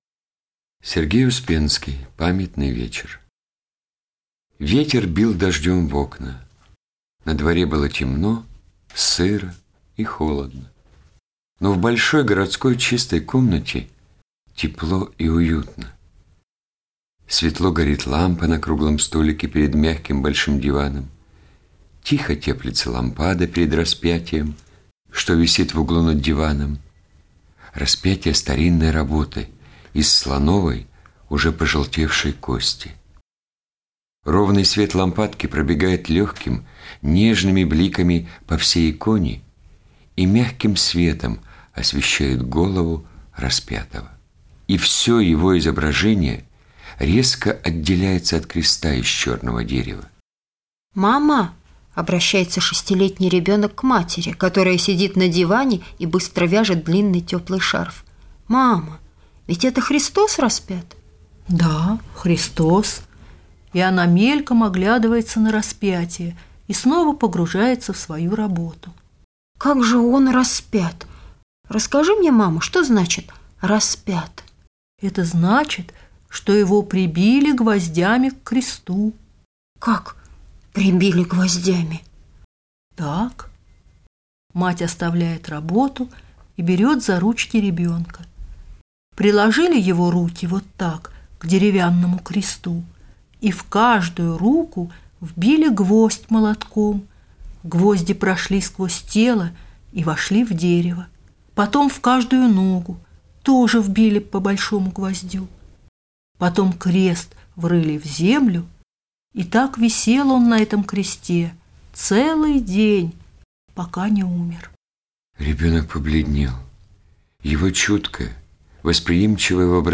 Памятный вечер - аудио рассказ Успенского - слушать онлайн